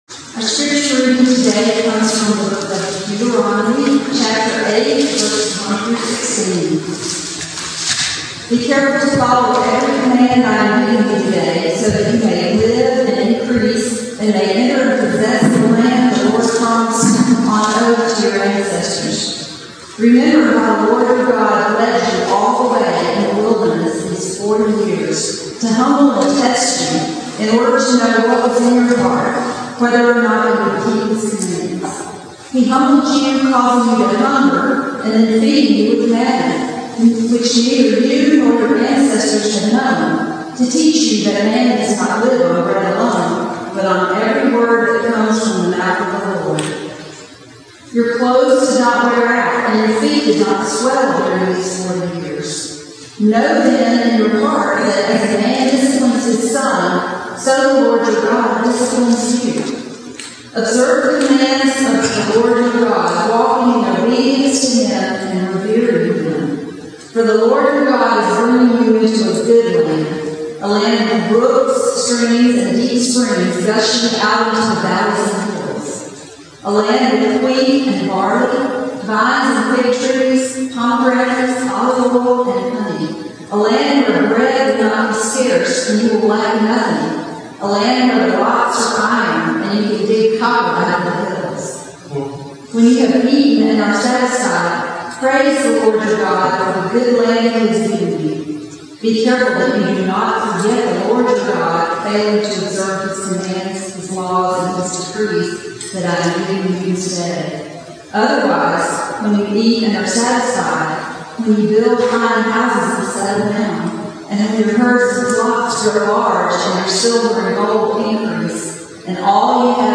Passage: Deuteronomy 7:1-26 Service Type: Sunday Morning